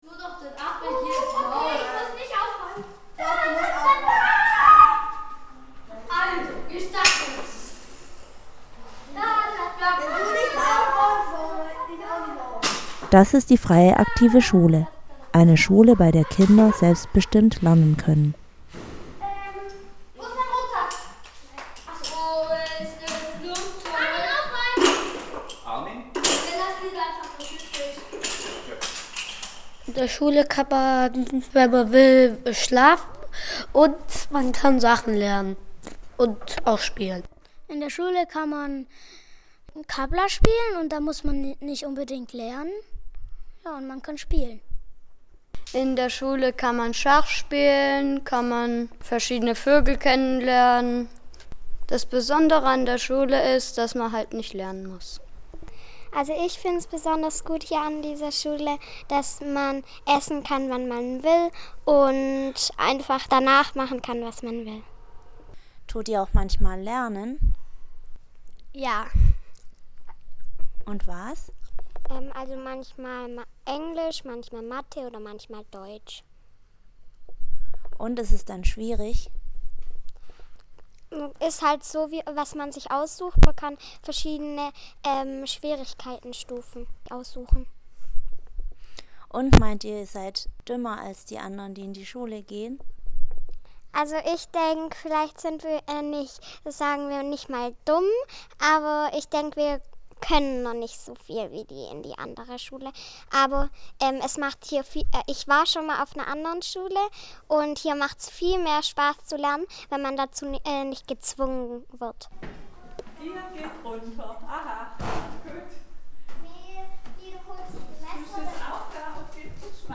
kurzer Beitrag zur freien aktiven Schule in Tübingen